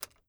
keys2.wav